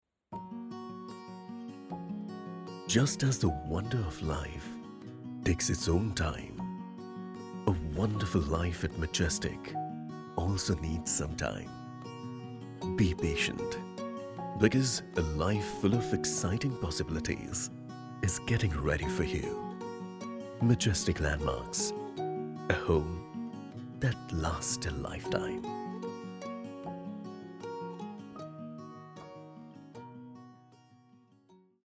Whenever you need a deep, baritone, sonorous voice to show your product to the world, you can get all of it from my voice.
English Real Estate Walkthrough (Genre - Soft,Emotional,Caring).mp3